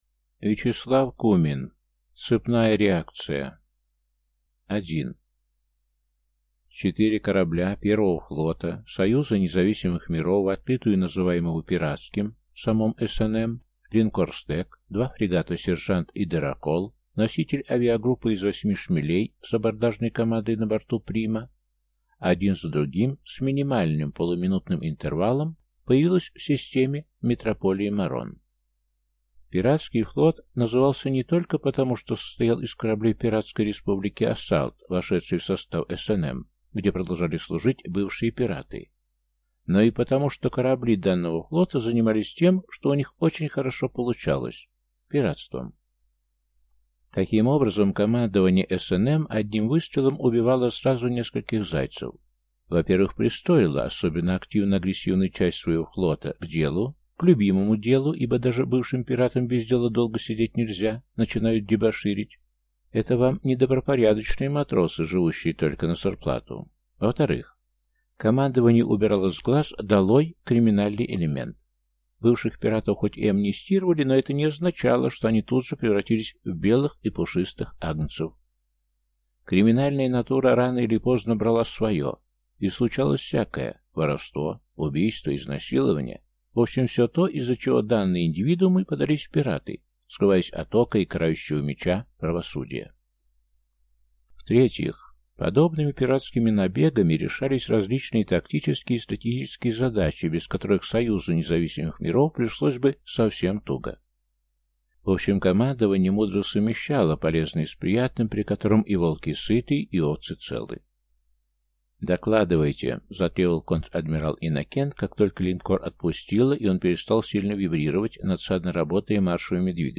Аудиокнига Цепная реакция | Библиотека аудиокниг